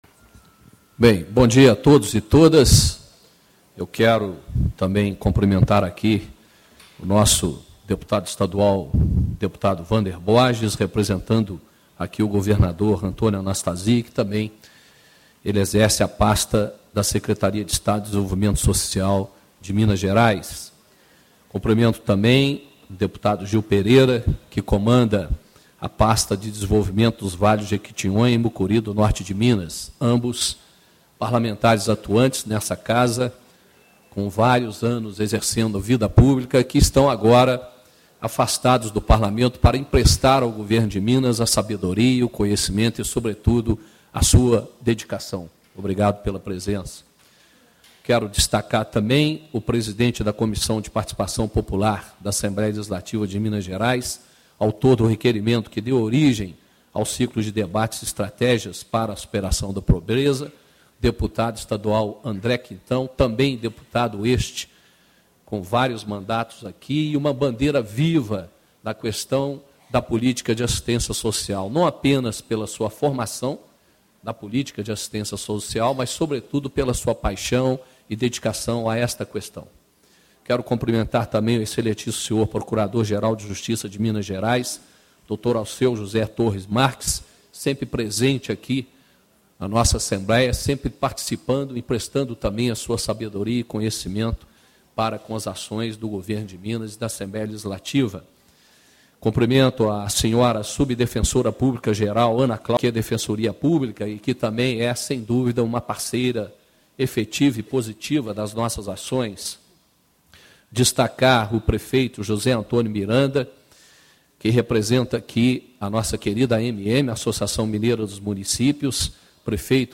Ciclo de Debates Estratégias para Superação da Pobreza
Discursos e Palestras